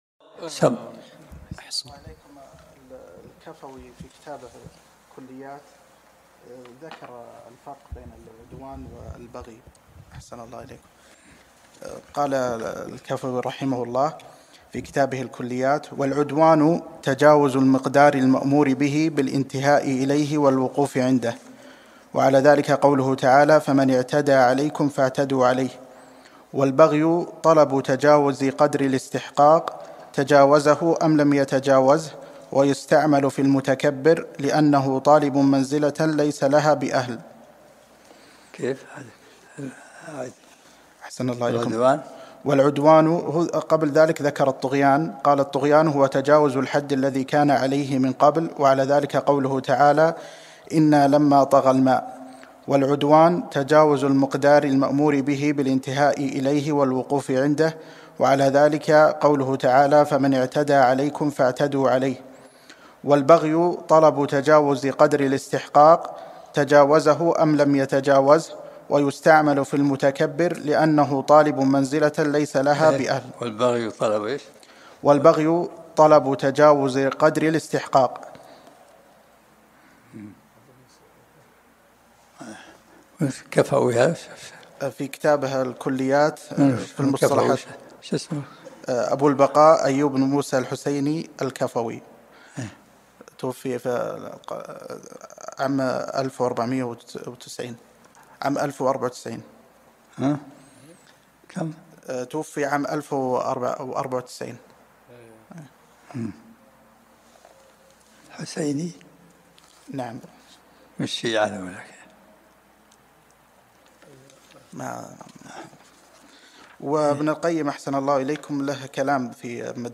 الدرس العاشر من سورة النحل